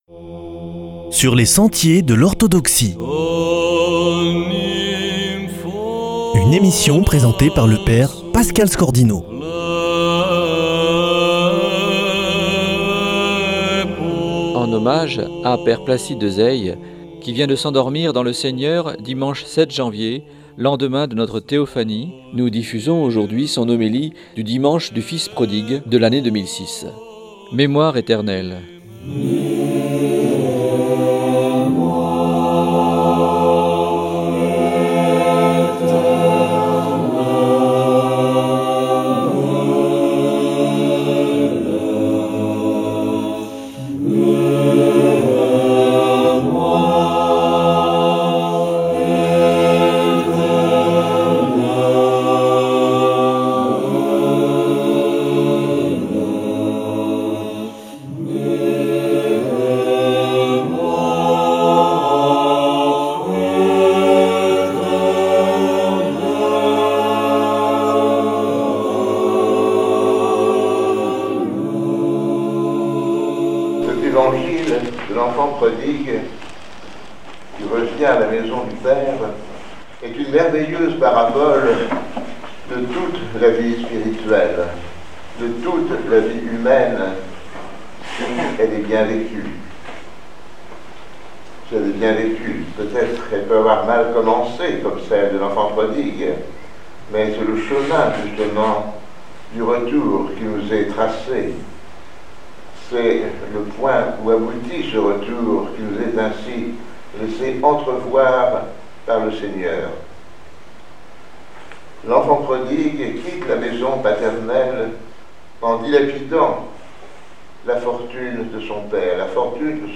homélie 2006 sur la parabole de l'Enfant Prodigue